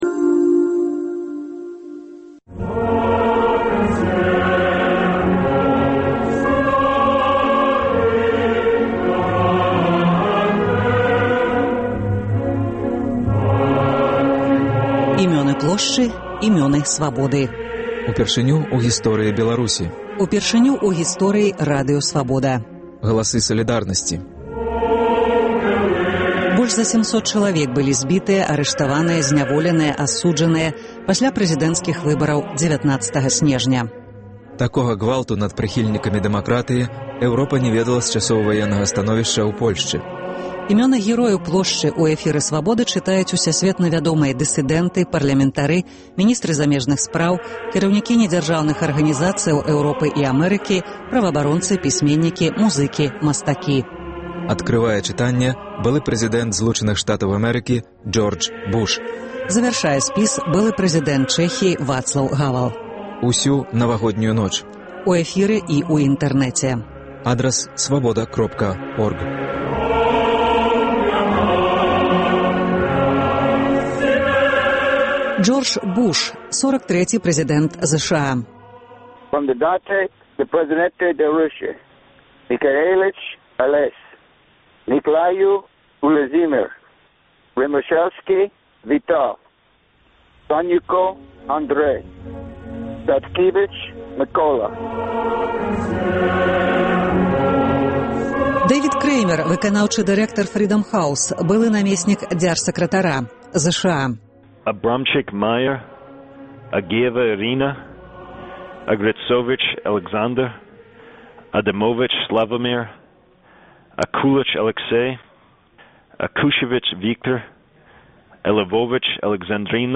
Імёны герояў Плошчы ў эфіры Свабоды чытаюць усясьветна вядомыя дысыдэнты, парлямэнтары, міністры замежных справаў, кіраўнікі недзяржаўных арганізацыяў Эўропы і Амэрыкі, праваабаронцы, пісьменьнікі, музыкі, мастакі. Адкрывае чытаньне былы прэзыдэнт Злучаных Штатаў Джордж Буш. Завяршае былы прэзыдэнт Чэхіі Вацлаў Гавэл.